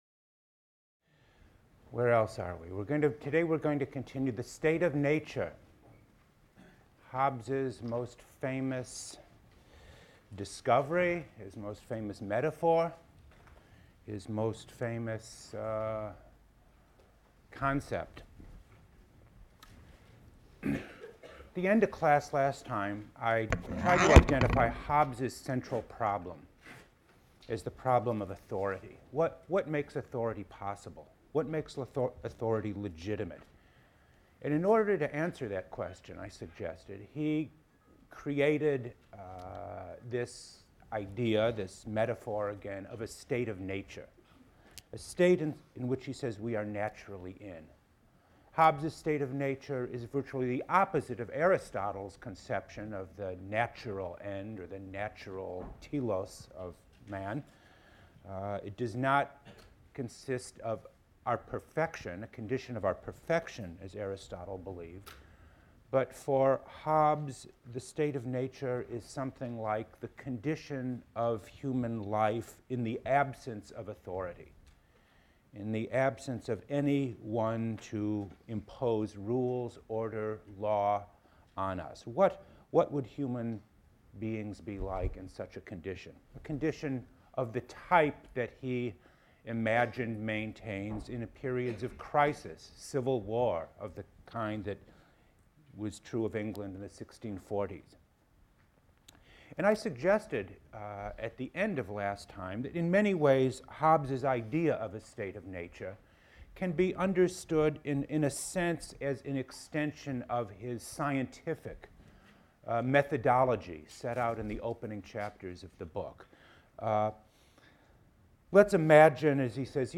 PLSC 114 - Lecture 13 - The Sovereign State: Hobbes, Leviathan | Open Yale Courses